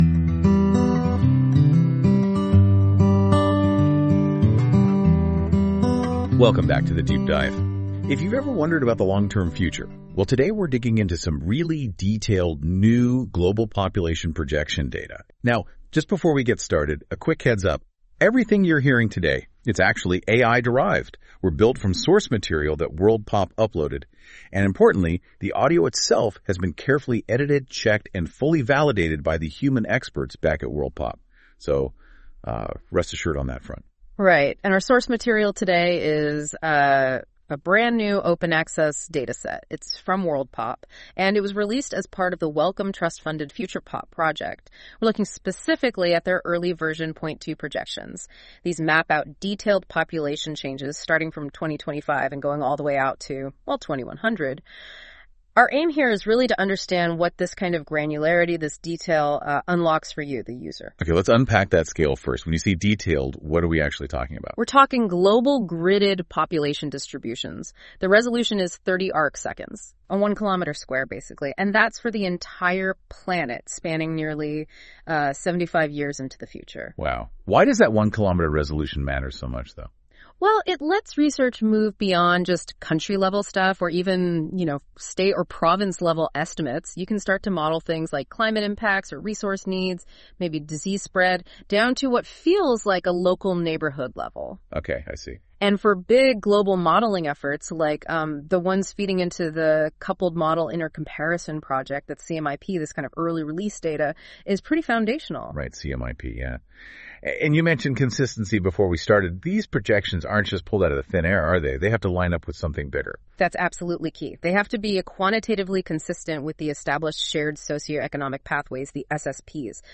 Music: My Guitar, Lowtone Music, Free Music Archive (CC BY-NC-ND)